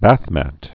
(băthmăt, bäth-)